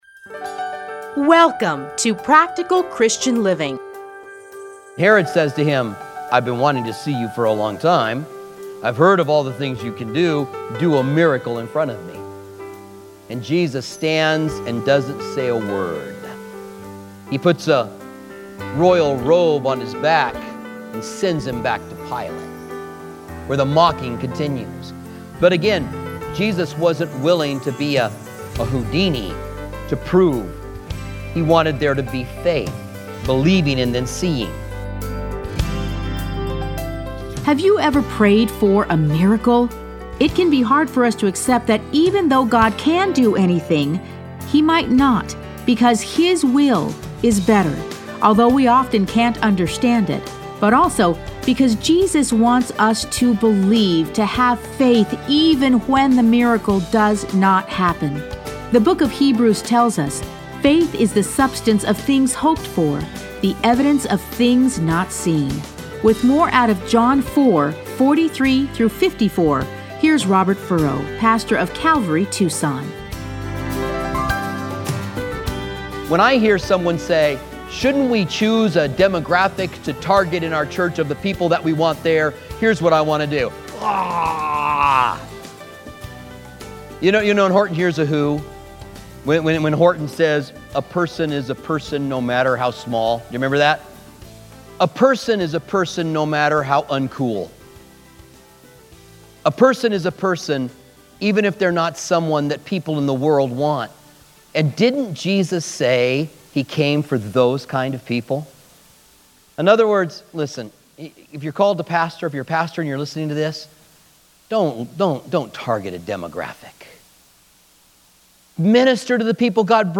Listen to a teaching from John 4:43-54.